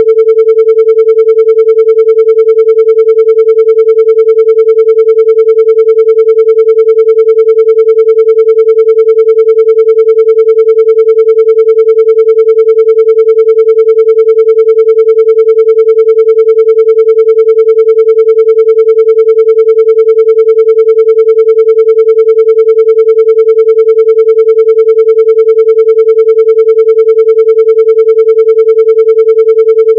An effective method for doing so is to get two frequencies to ‘beat’* together at the brain wave rhythm rate.
The files are based on pure Sine waves around 440 Hz (A)  and are therefore a little hard on the ear, however, they accurately represent the frequencies of interest.
On 440 Hz carrier wave
Alpha10.wav